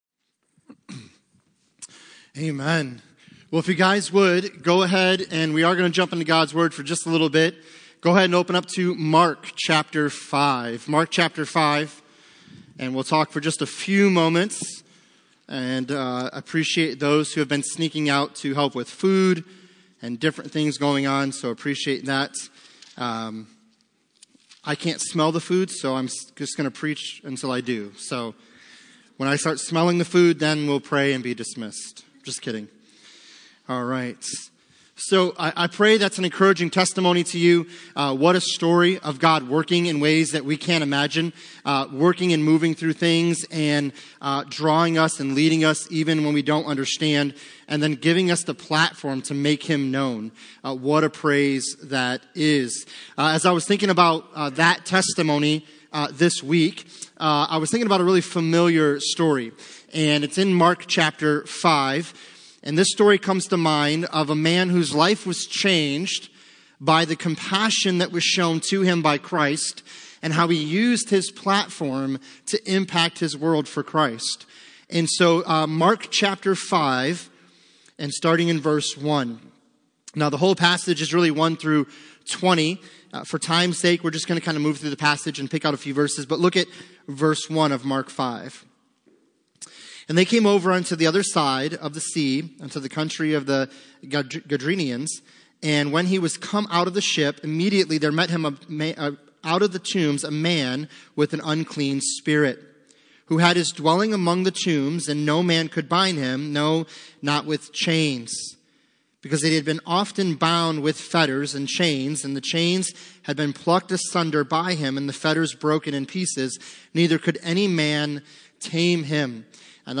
Passage: Mark 5:1-20 Service Type: Sunday Morning